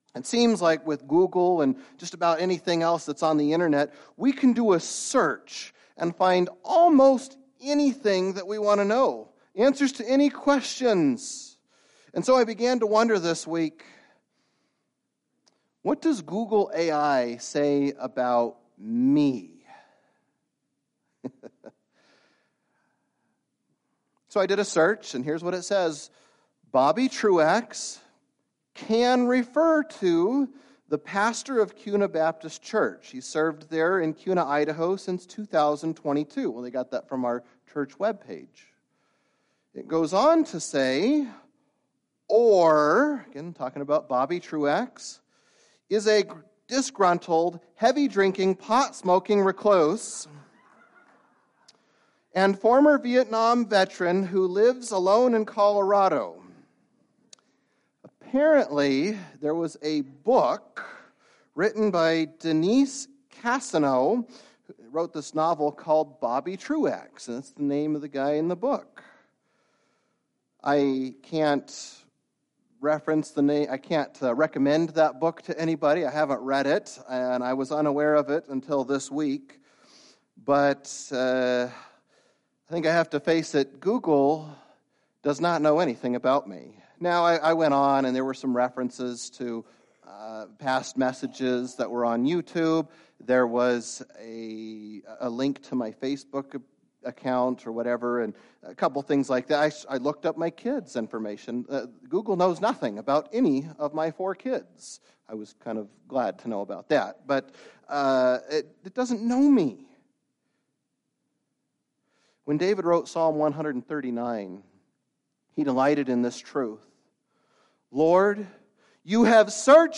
A Mother's Day Message